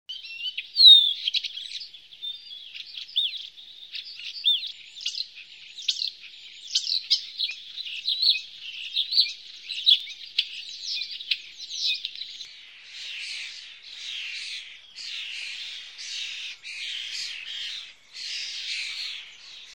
Spreeuw
Spreeuw.mp3